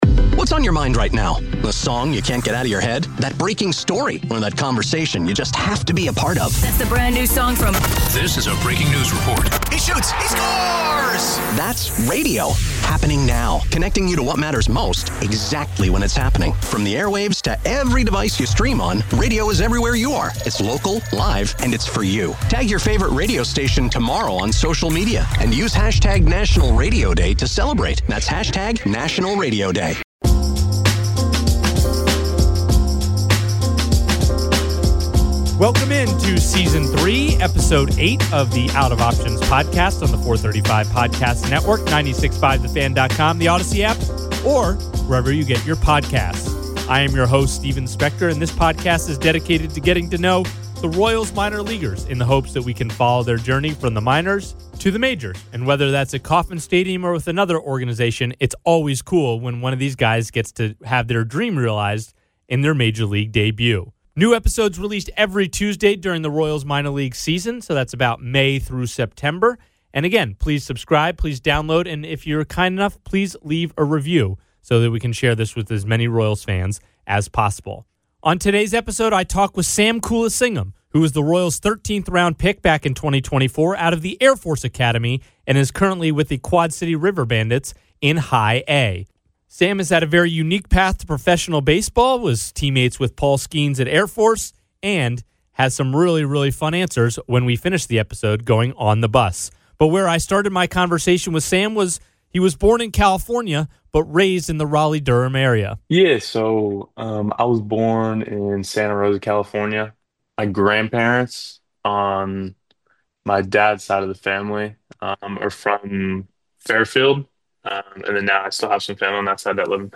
An interview based podcast with the goal of getting to know the next wave of talent in the Royals organization.